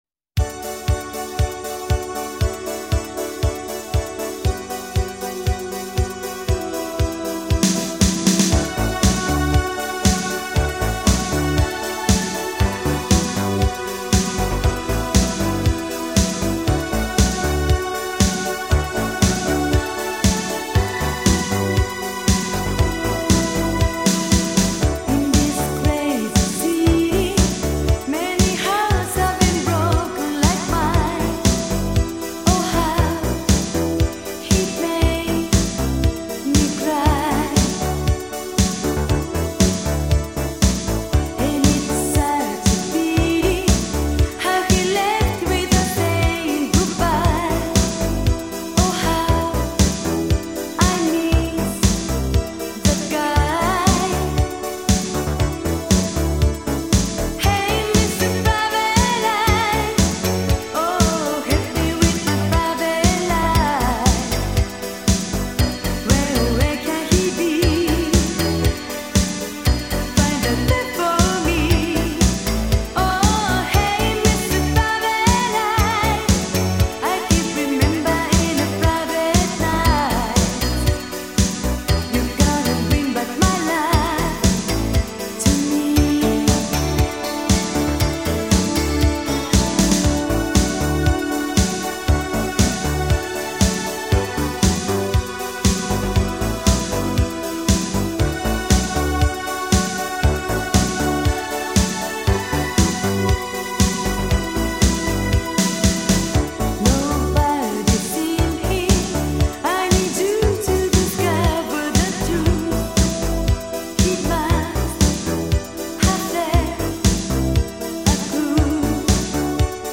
Ridiculously catchy